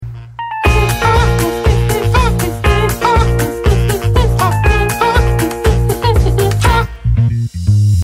Play, download and share Seagul original sound button!!!!
seagulls-stop-it-now-a-bad-lip-reading-of-the-empire-strikes-back2.mp3